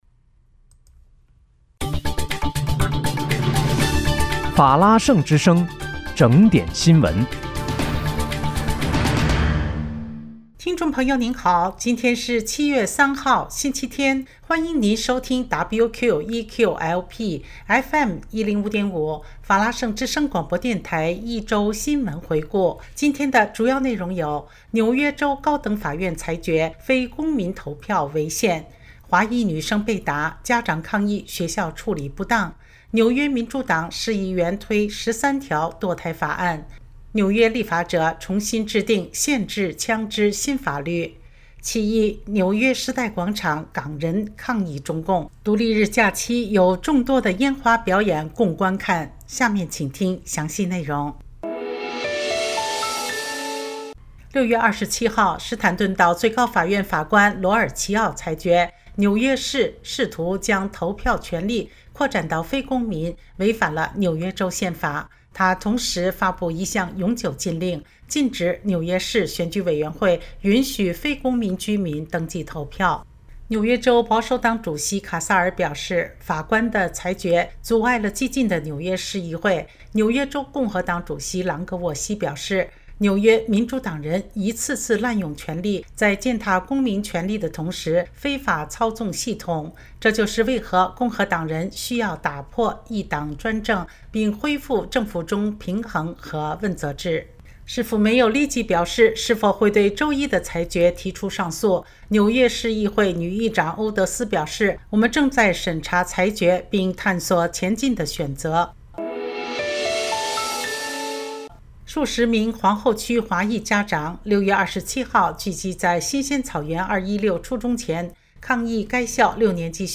7月3日（星期日）一周新闻回顾